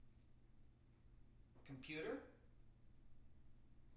wake-word
tng-computer-359.wav